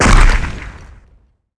创烂.wav